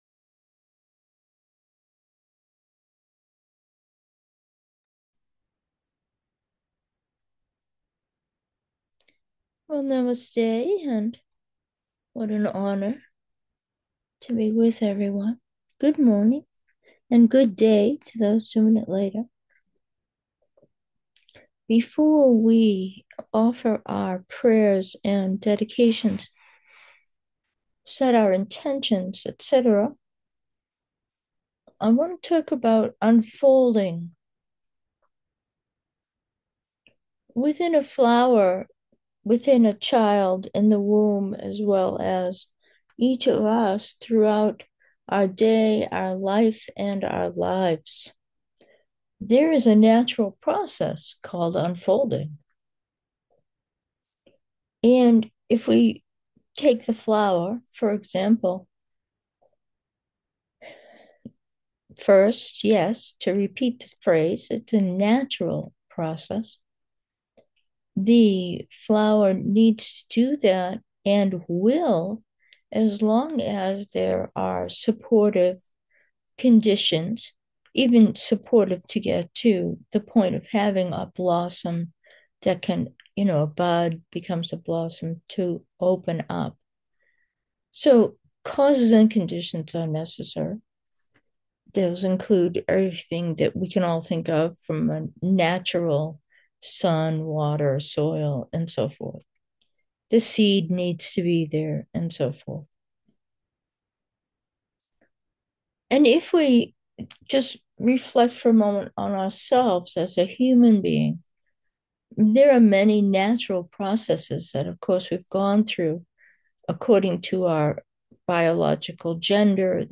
Meditation: with intention 4 – natural unfolding